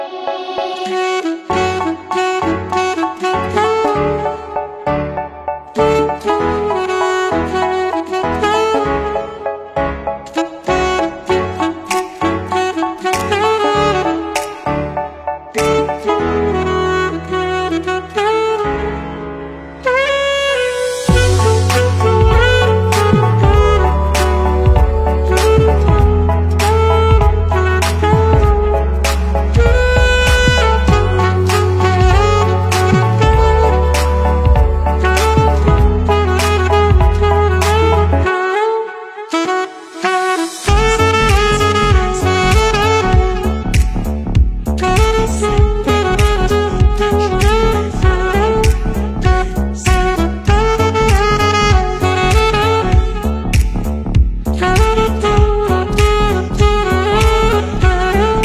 • Качество: 320, Stereo
красивые
без слов
инструментальные
пианино
медленные
Саксофон
кавер